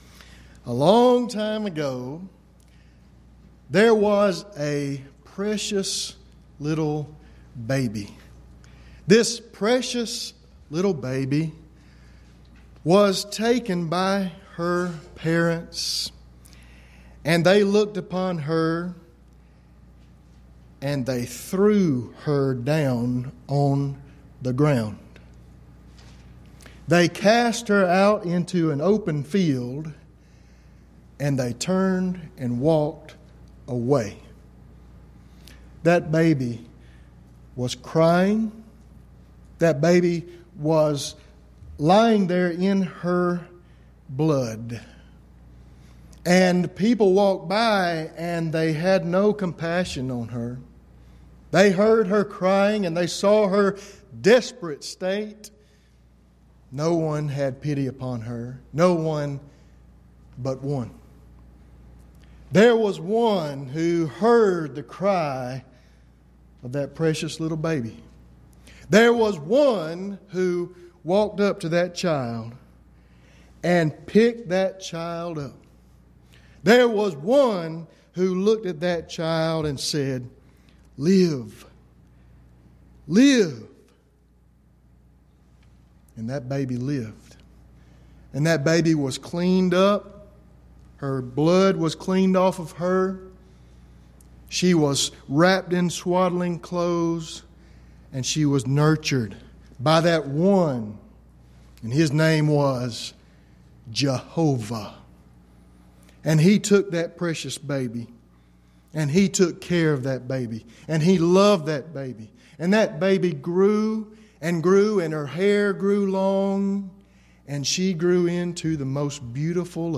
Event: 10th Annual Schertz Lectures
lecture